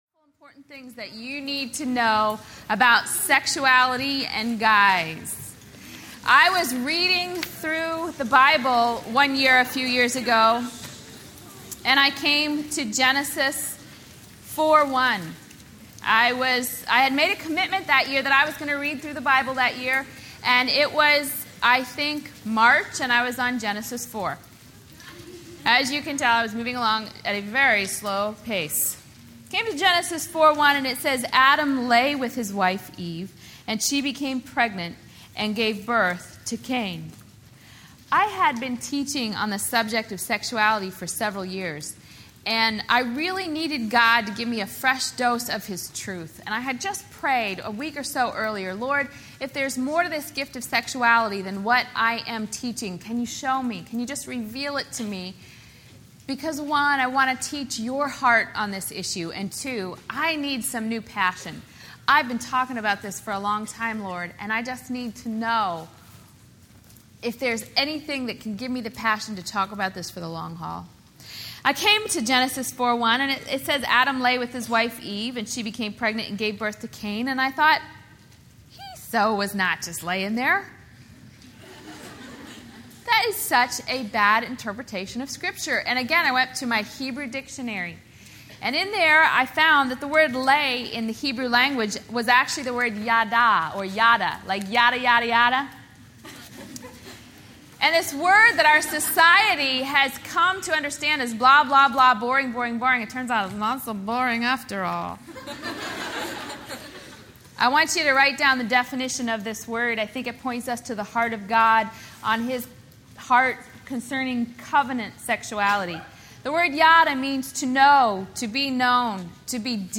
Lies Young Women Believe Teen Track—Sexuality | True Woman '10 Fort Worth | Events | Revive Our Hearts